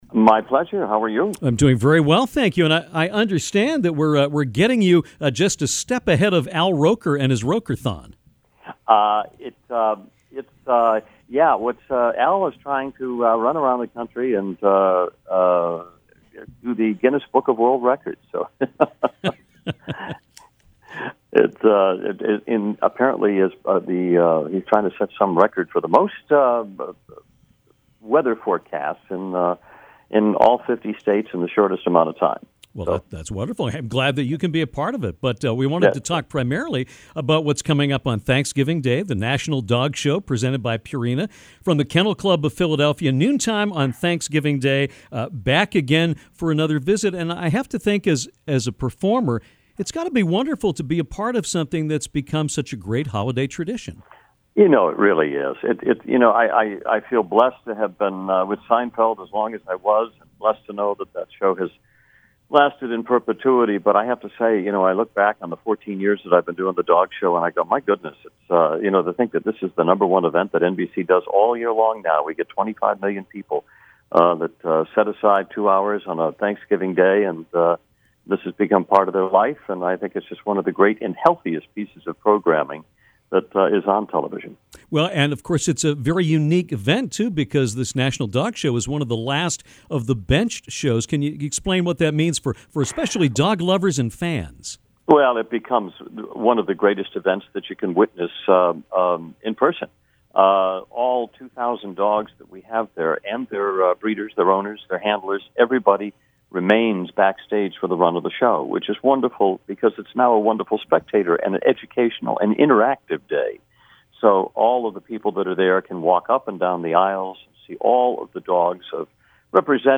John O’Hurley talks National Dog Show and recites un-aired J. Peterman monologue from “Seinfeld”